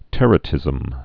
(tĕrə-tĭzəm)